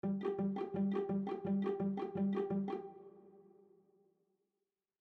RythmGame/SimpleGame/media/chords/variation1/G.mp3 at 5a1423d8dbc89bbc914ef3b3eb56c4addd643de3